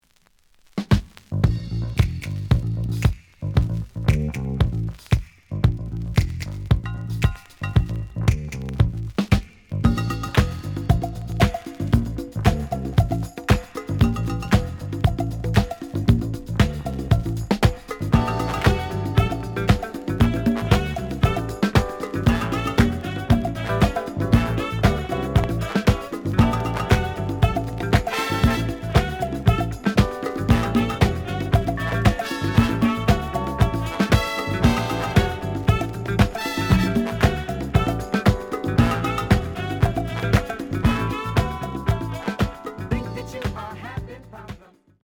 The audio sample is recorded from the actual item.
●Genre: Disco
Some noise on both sides.)